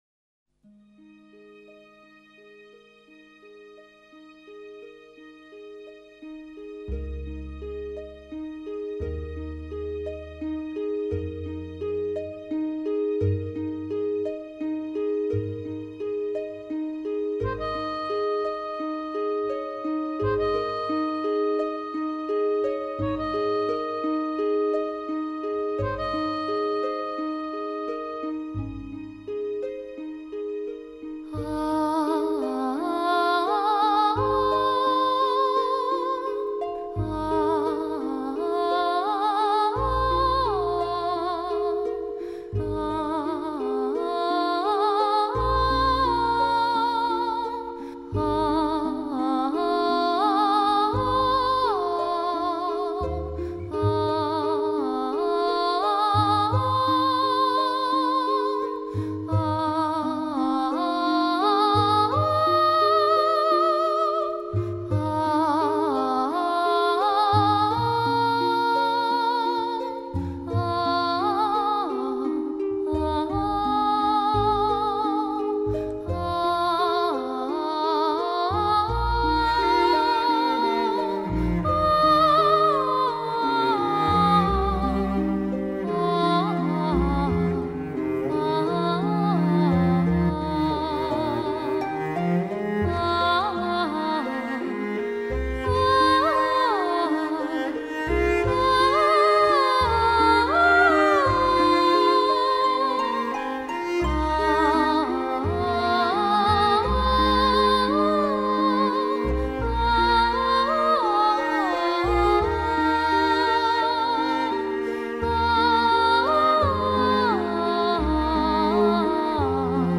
整个专辑给人以清新淡雅的感觉。